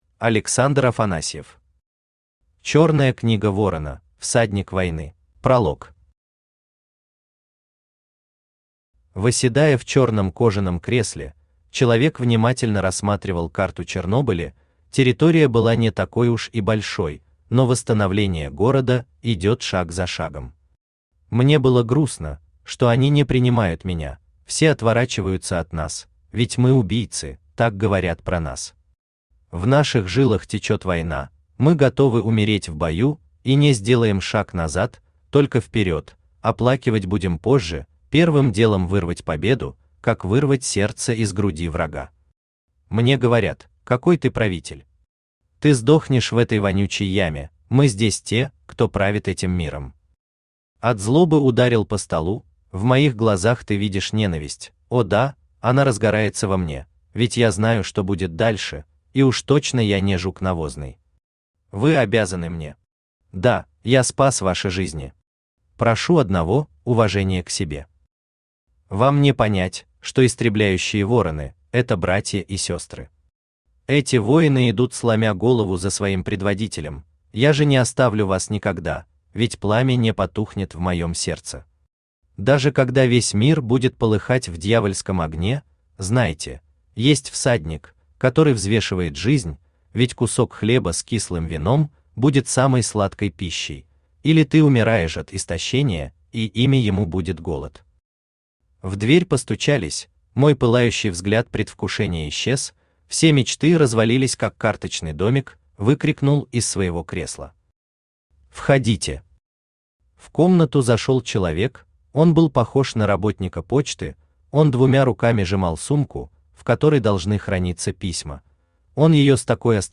Aудиокнига Чёрная книга ворона: всадник войны Автор Александр Константинович Афанасьев Читает аудиокнигу Авточтец ЛитРес.